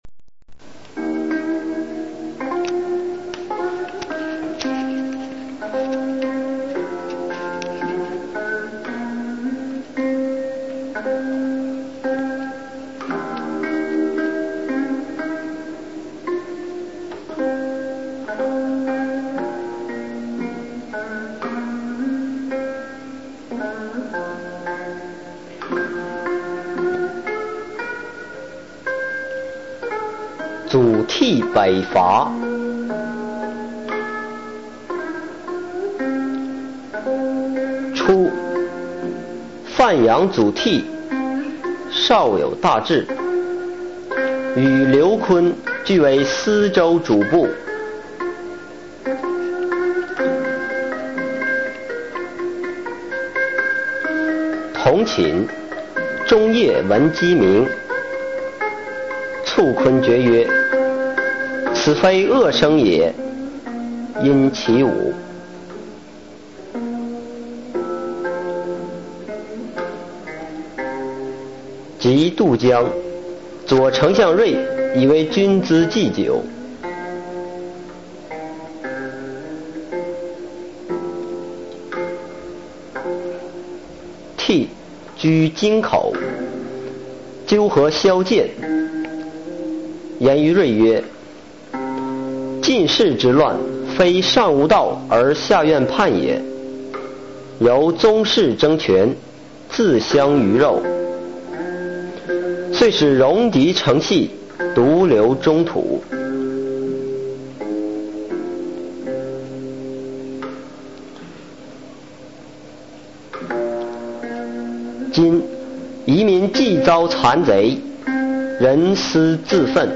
司马光《祖逖北伐》原文、注释和译文（含mp3朗读）